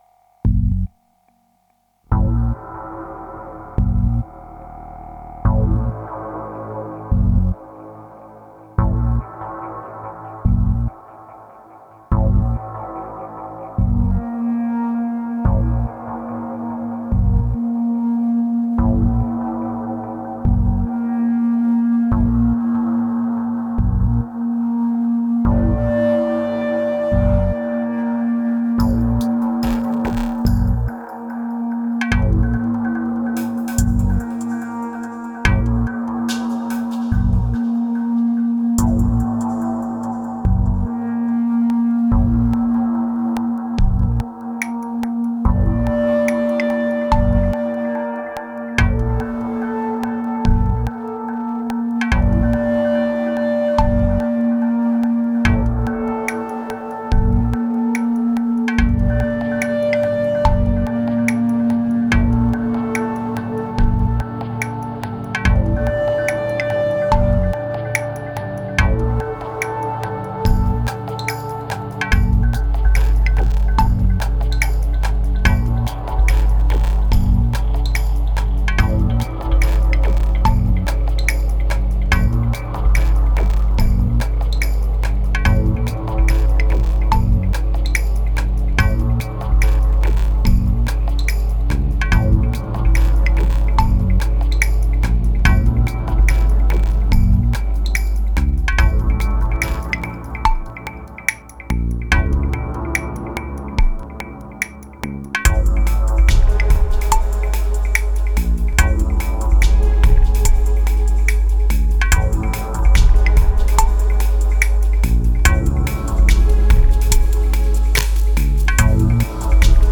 2150📈 - 38%🤔 - 72BPM🔊 - 2014-05-17📅 - 8🌟